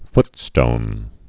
(ftstōn)